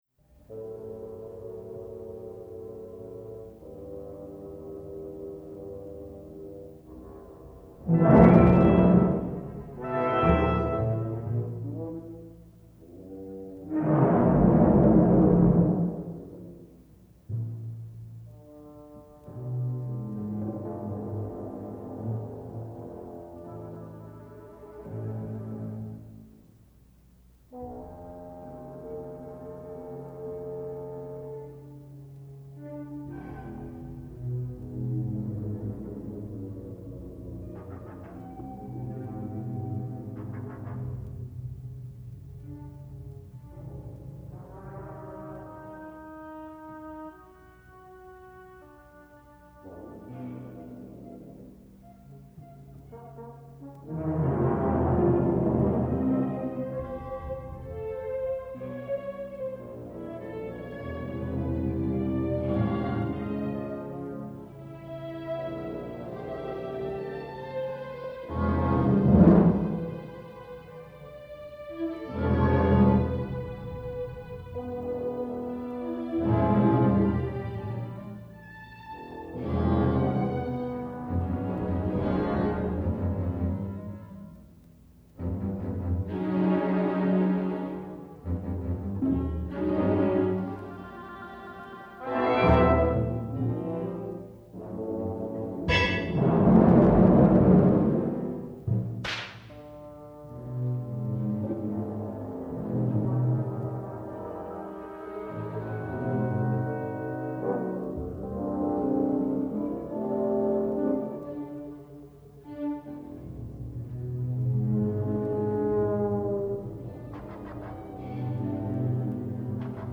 The tempo steadily increases.
The effect I have in mind is of demented birds.